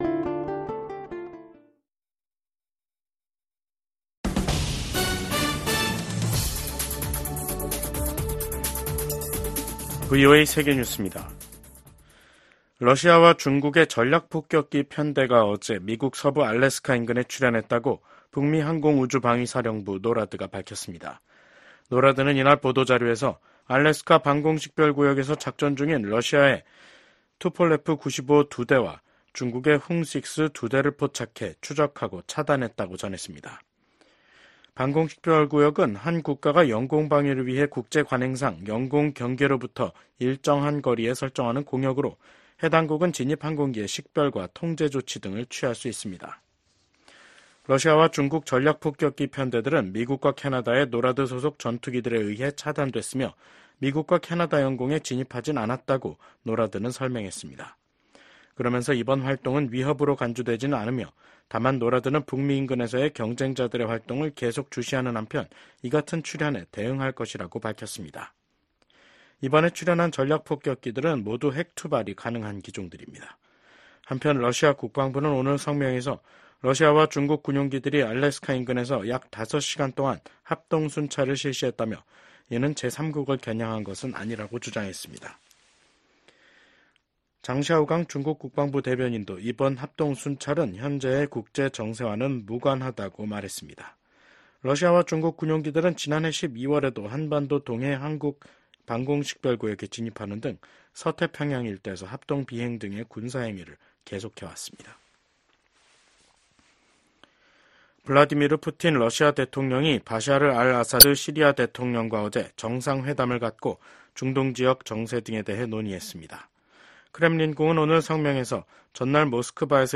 VOA 한국어 간판 뉴스 프로그램 '뉴스 투데이', 2024년 7월 25일 2부 방송입니다. 민주당 대선 후보직에서 사퇴한 조 바이든 미국 대통령이 대국민 연설을 통해 민주주의의 수호화 통합을 강조했습니다. 미국 정부가 북한의 미사일 관련 기술 개발을 지원한 중국 기업과 중국인에 신규 제재를 부과했습니다. 북한과 러시아의 관계가 급속도로 가까워지는 가운데 한국과 중국은 외교차관 전략대화를, 북한은 벨라루스와 외교장관 회담을 가졌습니다.